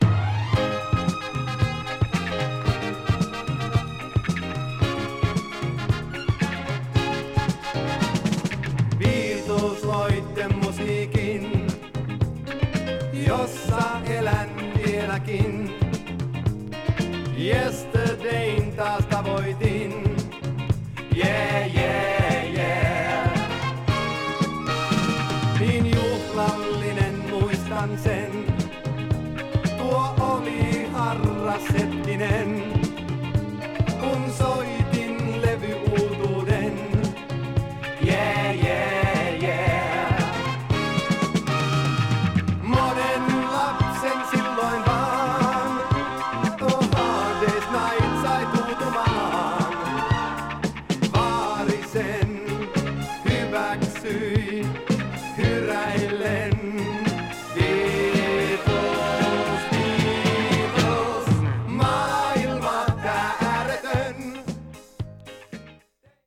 ユーロ・ディスコ・グルーヴ。